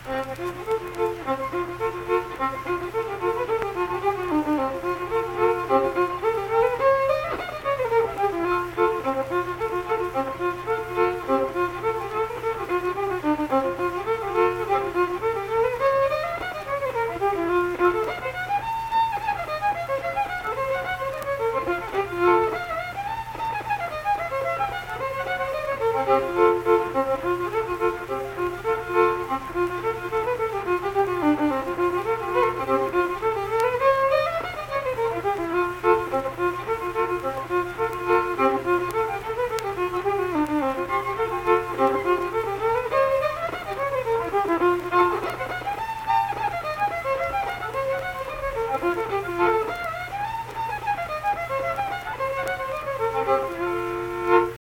Unaccompanied vocal and fiddle music
Instrumental Music
Fiddle
Saint Marys (W. Va.), Pleasants County (W. Va.)